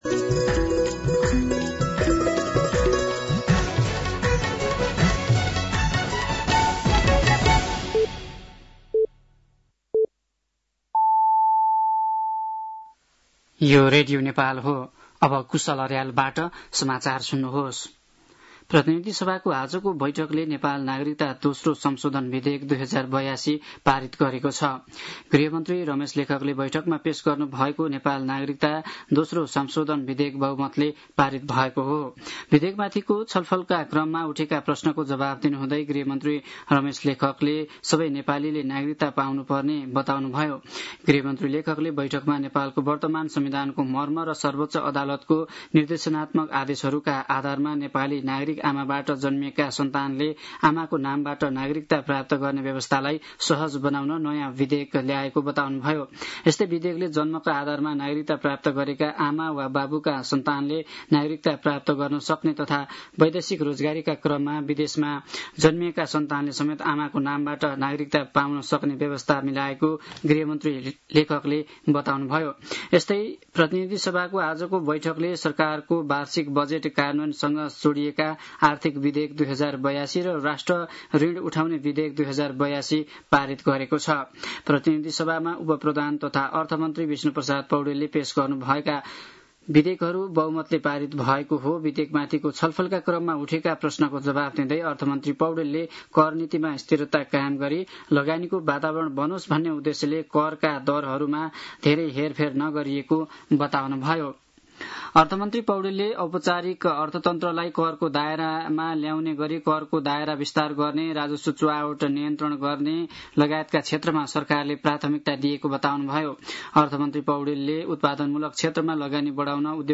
साँझ ५ बजेको नेपाली समाचार : १४ असार , २०८२
5.-pm-nepali-news-1-7.mp3